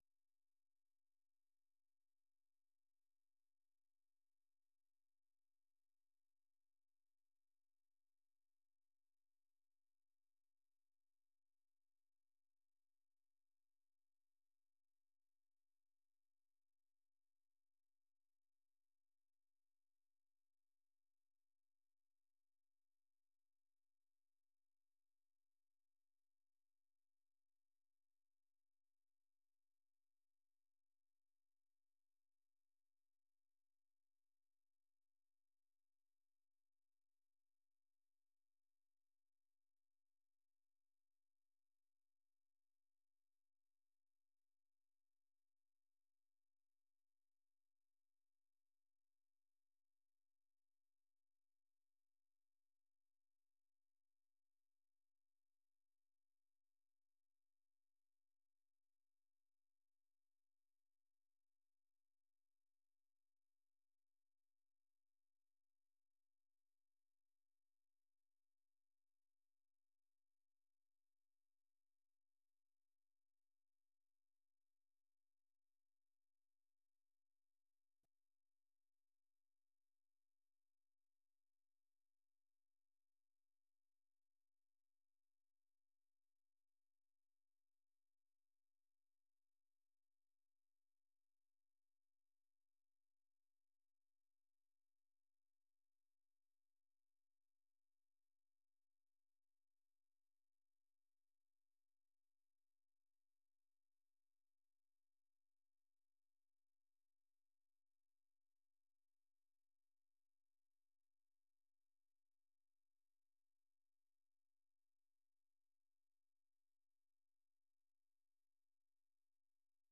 The URL has been copied to your clipboard 페이스북으로 공유하기 트위터로 공유하기 No media source currently available 0:00 0:59:58 0:00 생방송 여기는 워싱턴입니다 생방송 여기는 워싱턴입니다 아침 공유 생방송 여기는 워싱턴입니다 아침 share 세계 뉴스와 함께 미국의 모든 것을 소개하는 '생방송 여기는 워싱턴입니다', 아침 방송입니다.